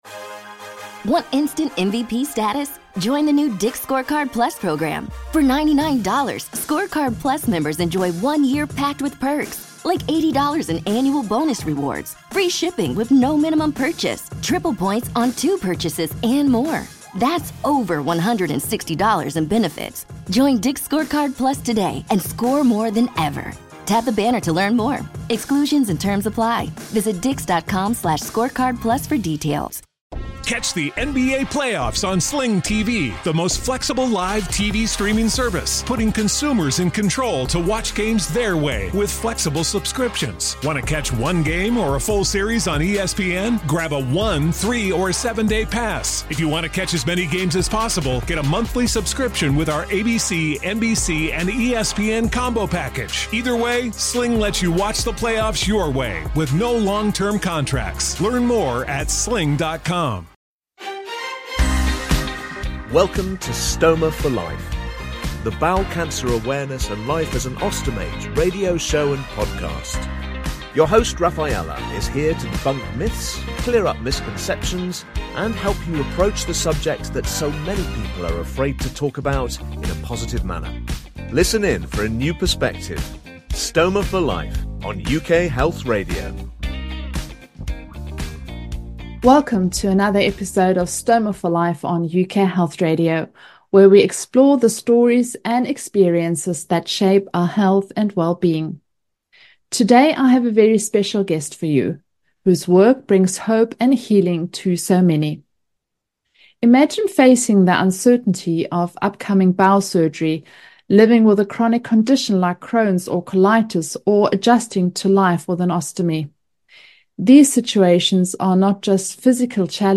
Surgeons, nurses, specialists in stoma & cancer care, IBS or Crohn's disease sufferers, people just like her, living with a stoma. She is here to debunk myths, clear up misconceptions and help you approach the subject that so many people are afraid to talk about, in a positive manner.